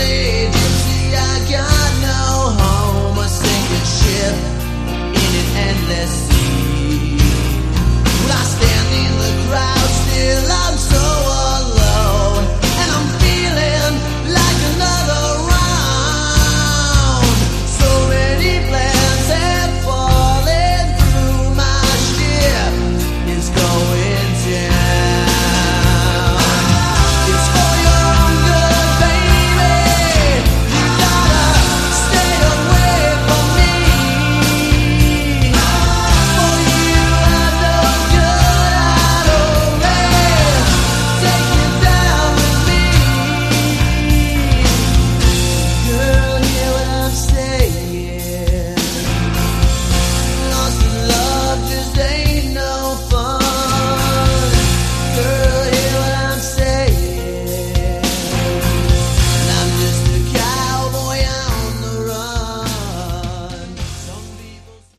Category: Sleaze - Glam